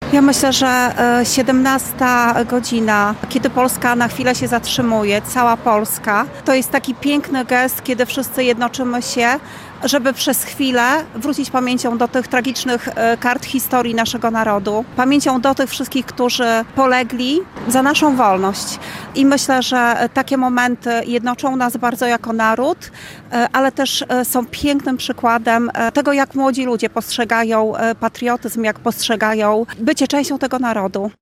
Wicestarosta Łomżyński Anna Gawrych podkreślała, że wydarzenia z przeszłości są cenną lekcją dla współczesnego społeczeństwa: